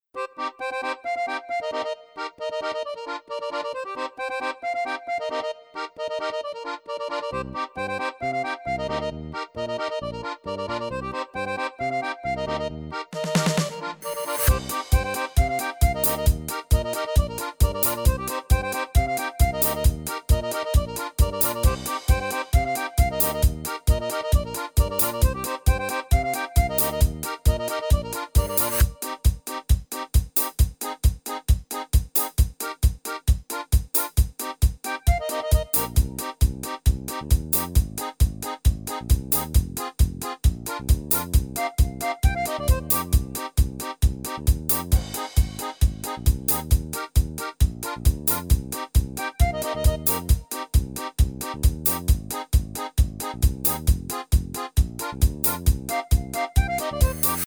Rubrika: Pop, rock, beat